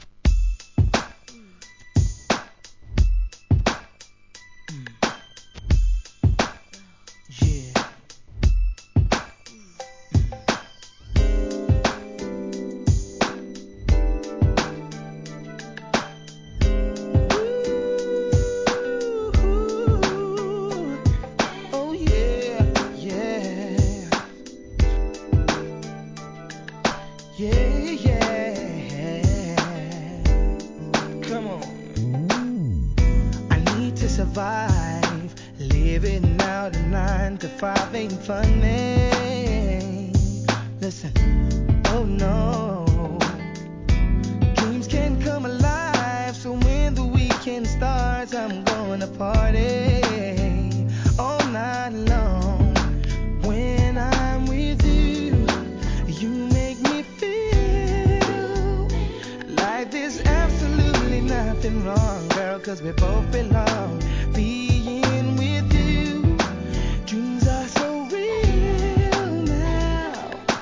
HIP HOP/R&B
良質なR&Bが揃ったコンピレーション!!